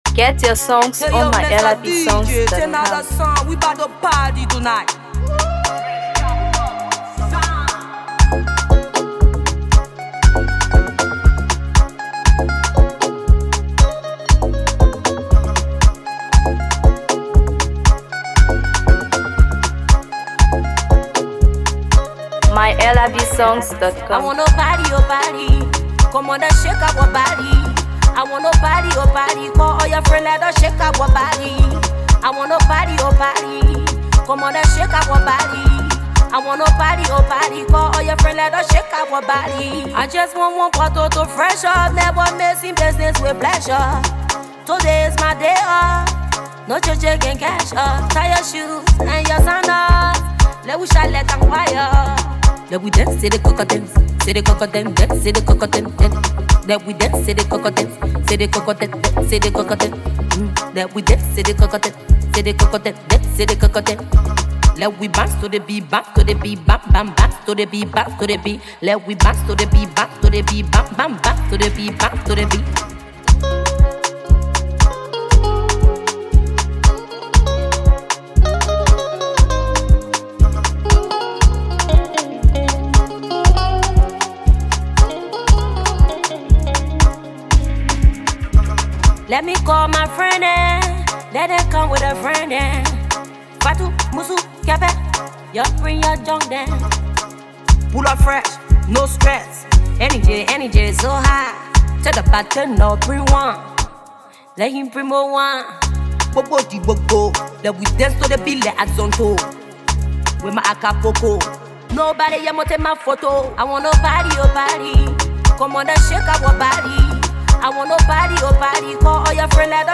Afro PopMusic
Afrobeat
Bursting with energy, rhythm, and celebration